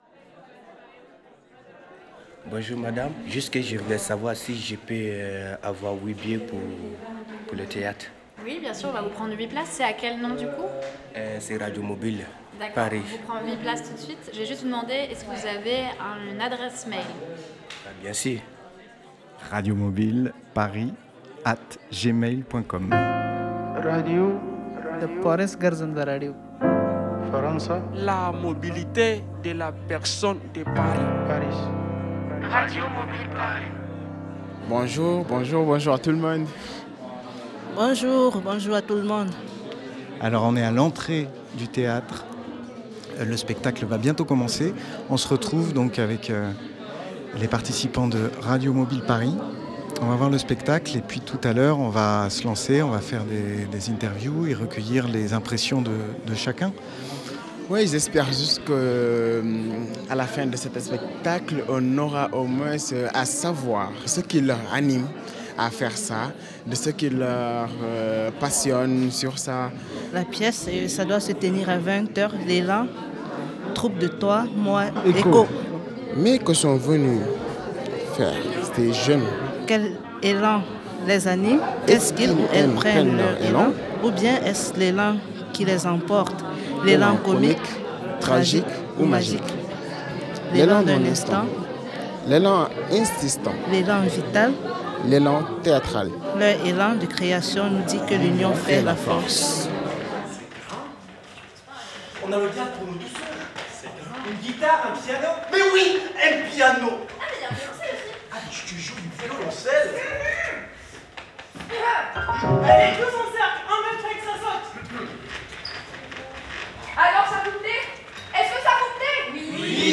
Retrouvez ci-dessous le premier reportage de Radio Mobile Paris enregistré lors du spectacle de la troupe Toi, Moi & Co, édition 2022.